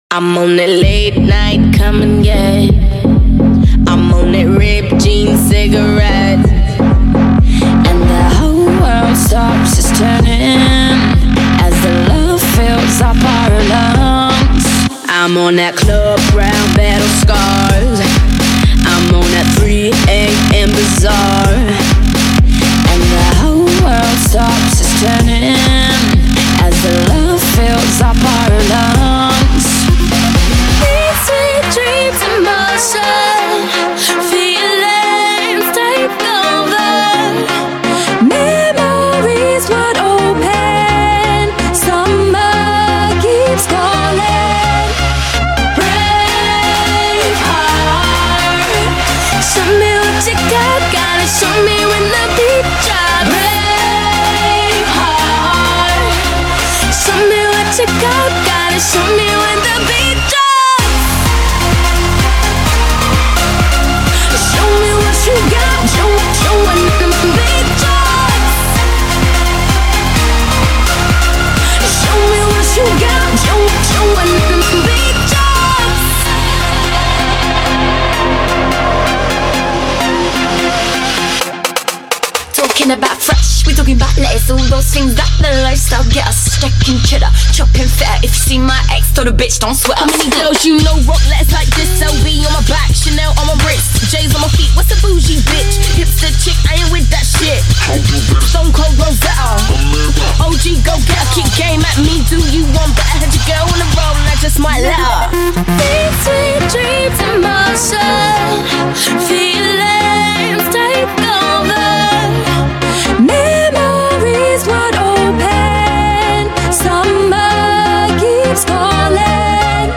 BPM64-128
Audio QualityCut From Video